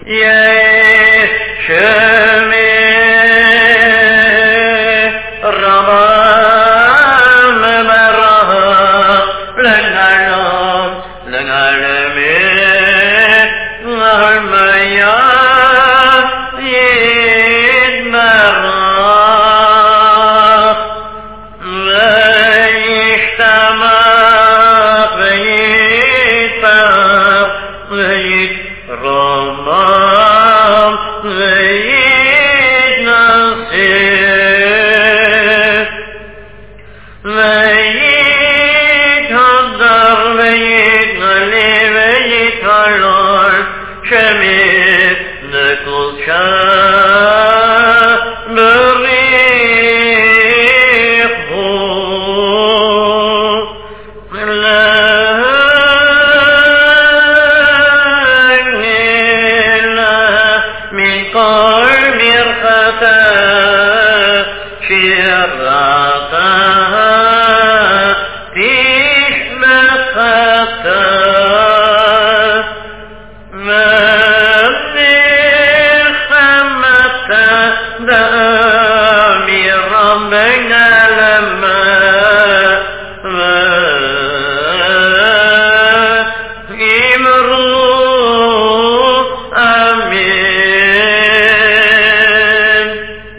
N25 Rosh Hashana ochtend-kadish - jehe sheme-pag 66.mp3